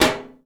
metal_tin_impacts_movement_rattle_07.wav